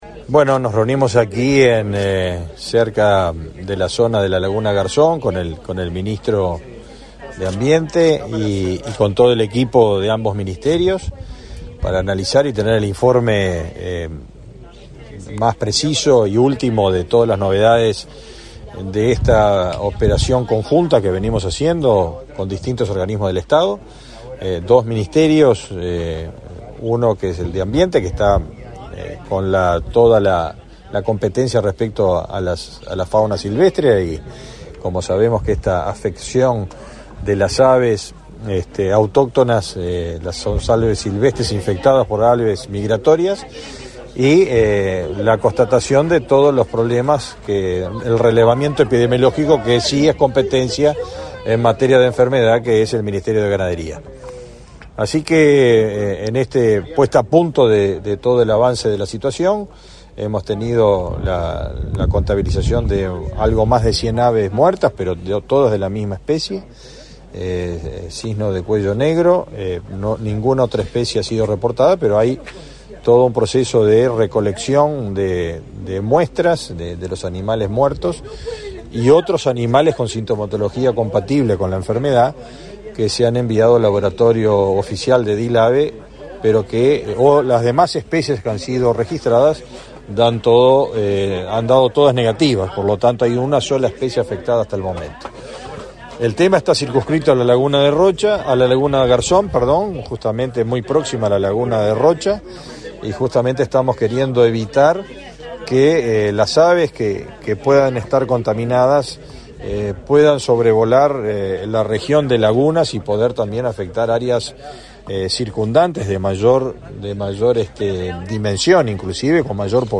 Declaraciones a la prensa del ministro de Ganadería, Agricultura y Pesca, Fernado Mattos